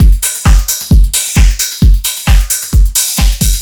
ELECTRO 12-R.wav